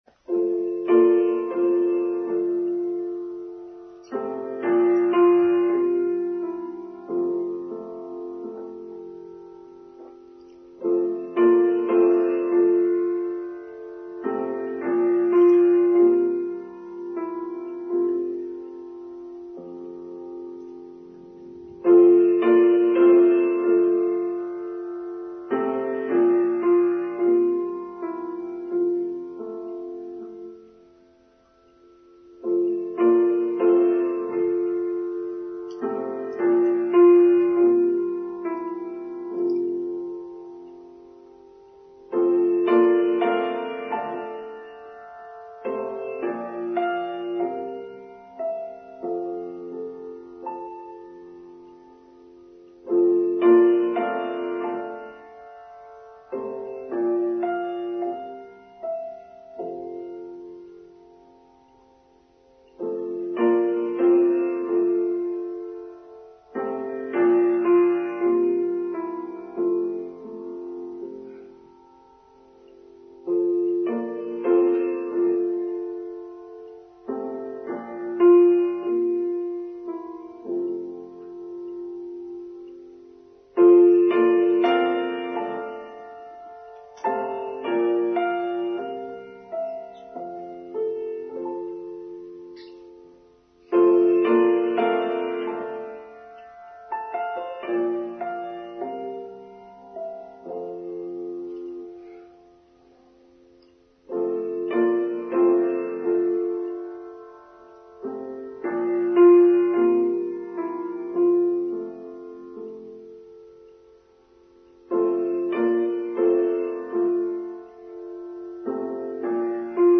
Harvest and Thanksgiving: Online Service for Sunday 1st October 2023
harvest-and-thanksgiving-trimmed.mp3